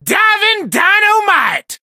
buzz_ulti_vo_03.ogg